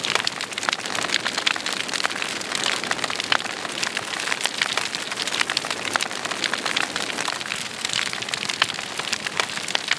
SFX_FireMedium_L.wav